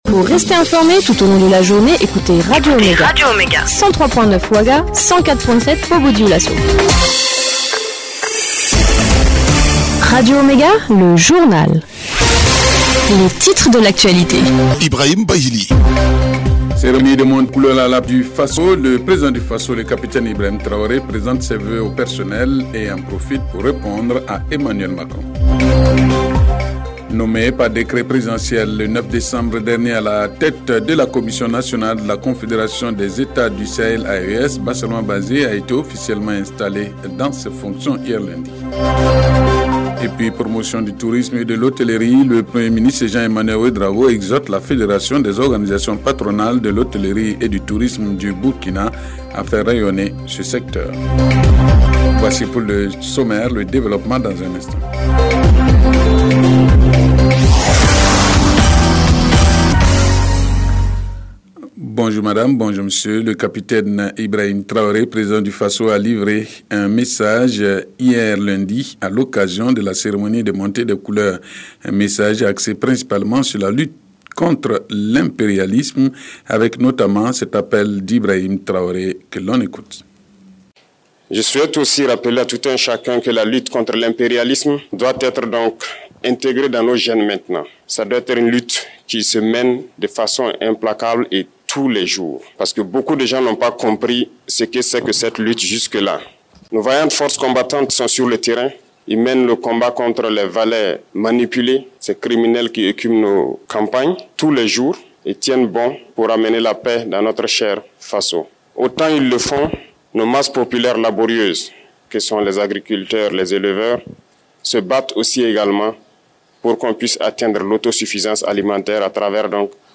Le journal de 12h15 du mardi 14 janvier 2024